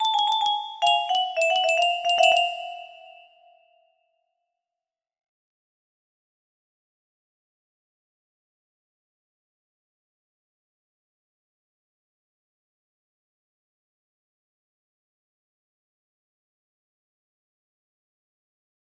green music box theme music box melody